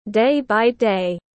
Ngày qua ngày tiếng anh gọi là day by day, phiên âm tiếng anh đọc là /deɪ baɪ deɪ/
Day by day /deɪ baɪ deɪ/